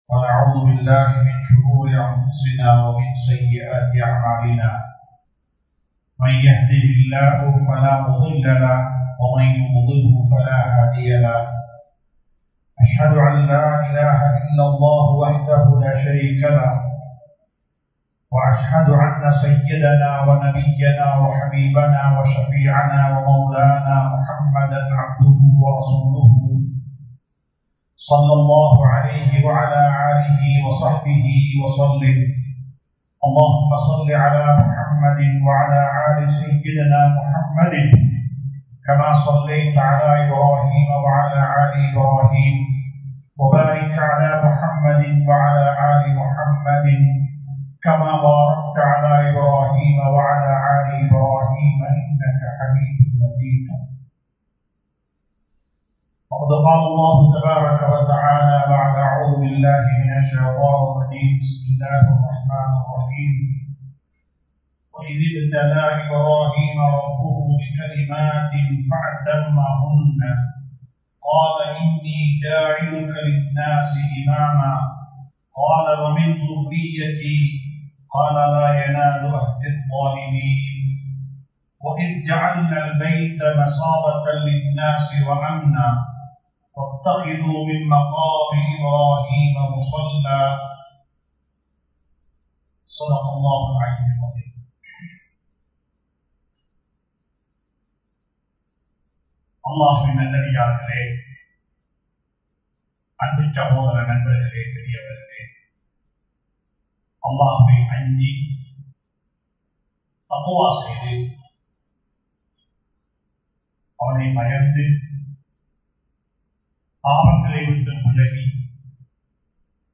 மரணித்தாலும் வாழும் மக்கள் | Audio Bayans | All Ceylon Muslim Youth Community | Addalaichenai
Colombo 06, Mayura Place, Muhiyadeen Jumua Masjith